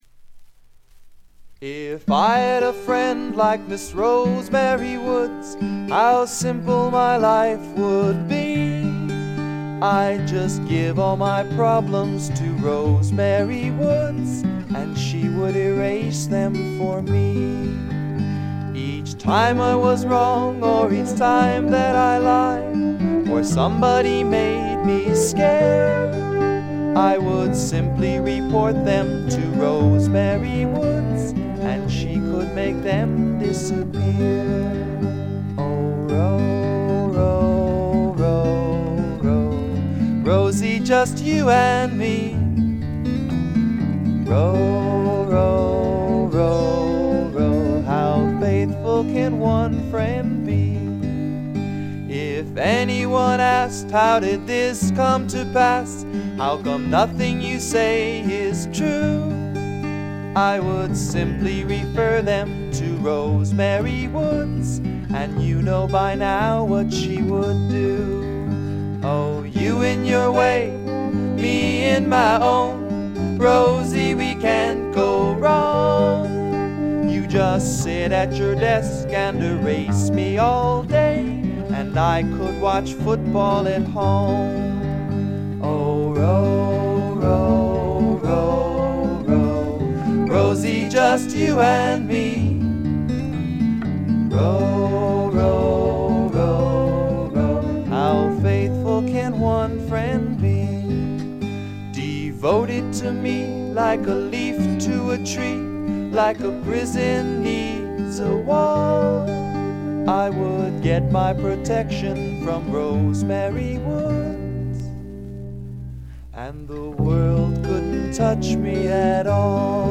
軽微なチリプチ少々。
しかし内容はいたってまともなフォーキー・シンガー・ソングライター作品です。
いかにも東部らしい静謐な空気感がただようなかに、愛すべきいとおしい曲が散りばめられた好盤です。
試聴曲は現品からの取り込み音源です。
French Horn [Two]
Guitar, Bass, Vocals